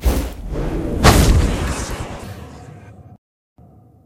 magic2.ogg